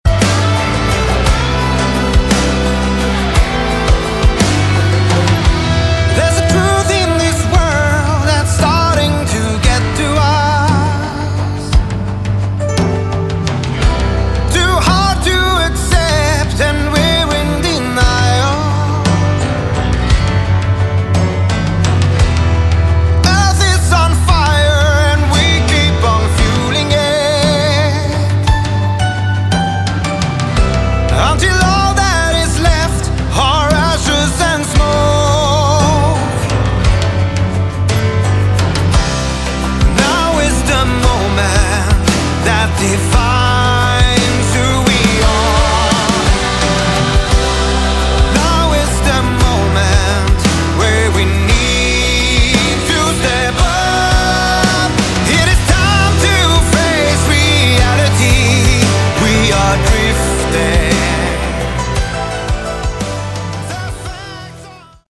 Category: Melodic Rock
vocals
guitar
keyboards
bass guitar
drums